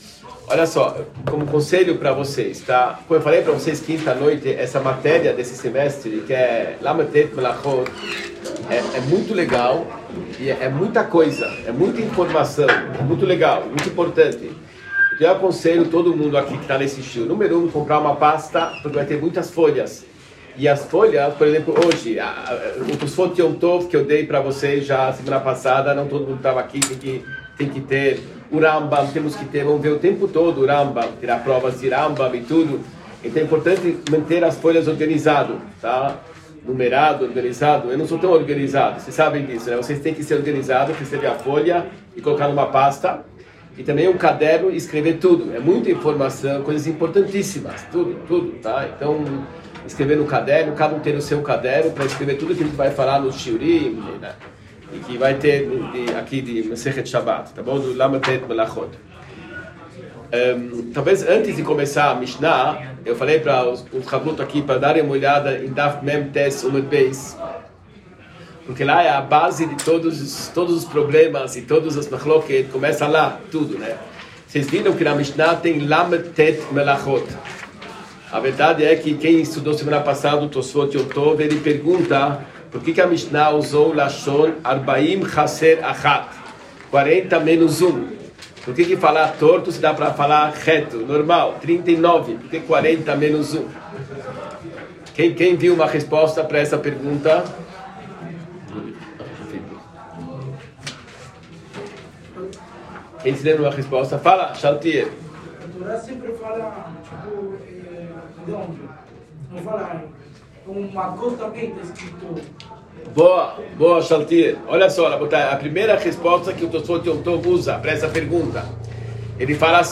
Shiur-39-Melachot-Shabat-Parte-01.mp3